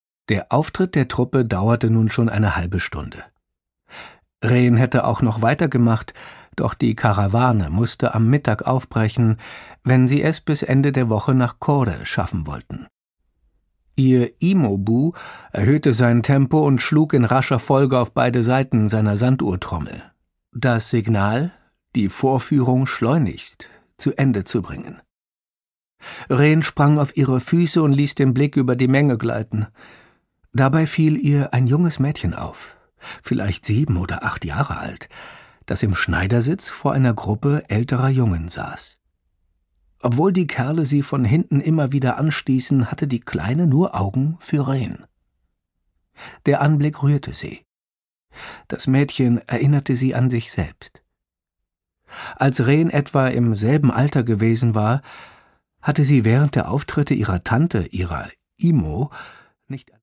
Produkttyp: Hörbuch-Download
fesselt mit seiner markanten Stimme von der ersten bis zur letzten Minute